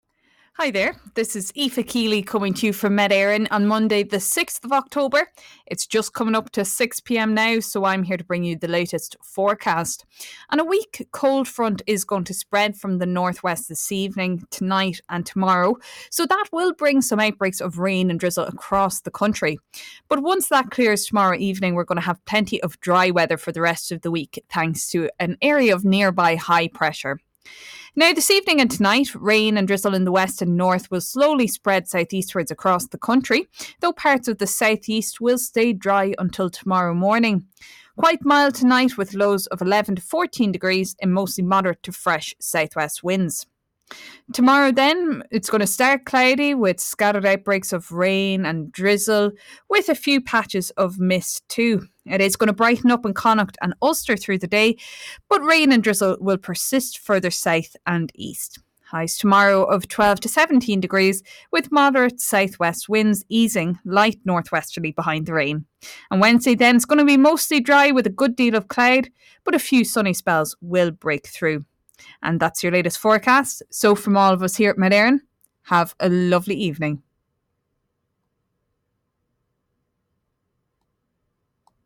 Weather Forecast from Met Éireann / Ireland's Weather 6pm Monday 6 October 2025